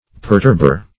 Search Result for " perturber" : The Collaborative International Dictionary of English v.0.48: Perturber \Per*turb"er\, n. One who, or that which, perturbs, or cause perturbation.